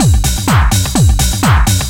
DS 126-BPM A01.wav